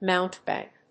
音節moun・te・bank 発音記号・読み方
/mάʊnṭɪb`æŋk(米国英語)/